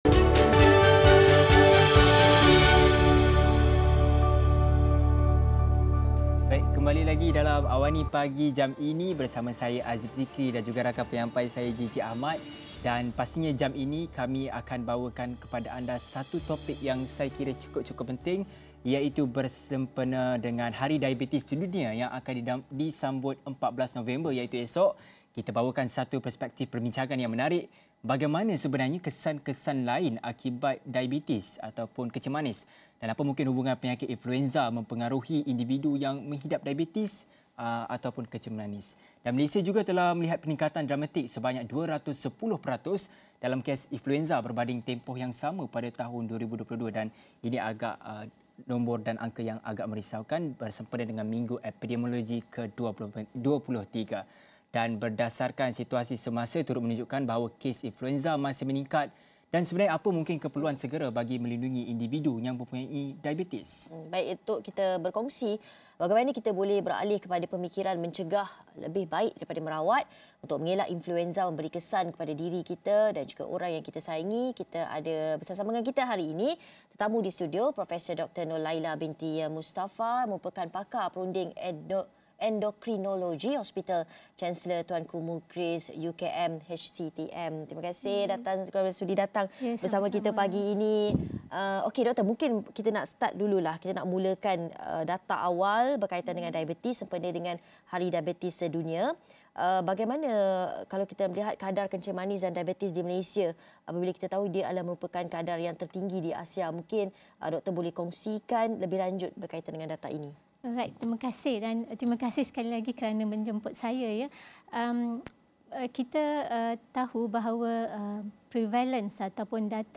diskusi